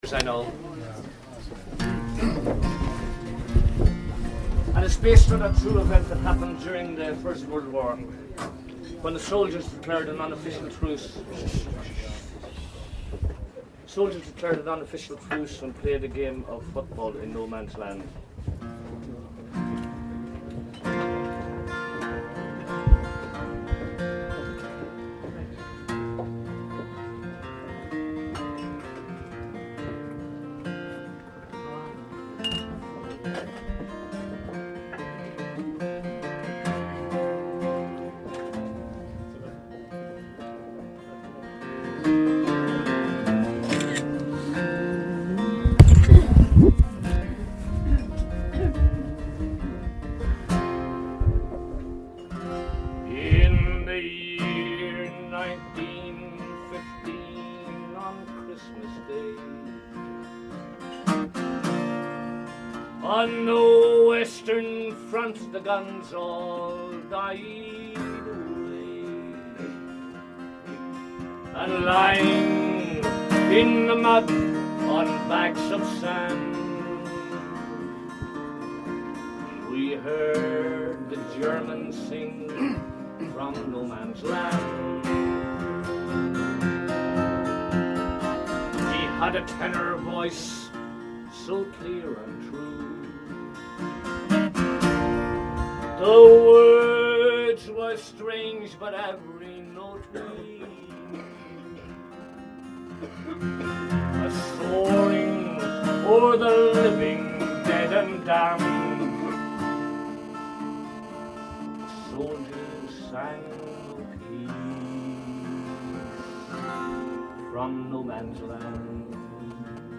Session (2) in John B Keane's pub ending Listowel Writers Week 2015 - raw